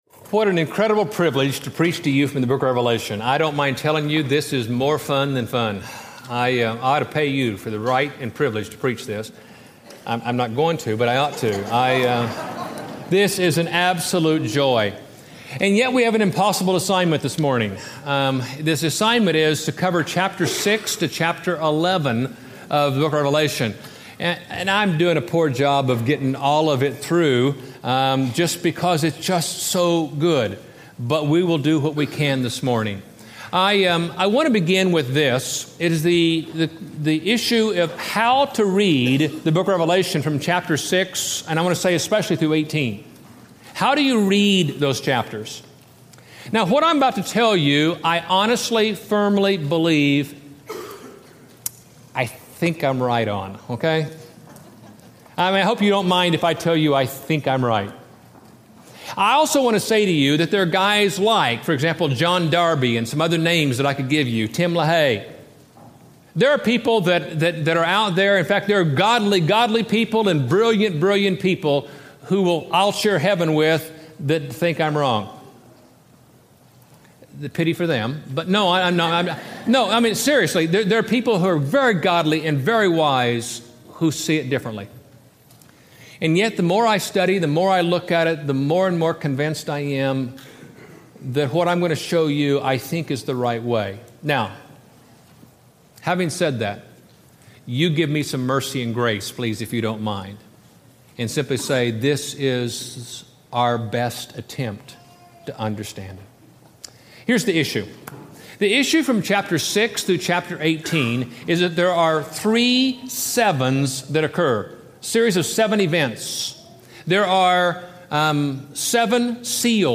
It Was the Best of Times, It Was the Worst of Times Preached at College Heights Christian Church May 6, 2007 Series: Living at Peace in a World Falling to Pieces Scripture: Revelation 6-11 Audio